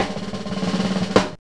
JAZZ FILL 7.wav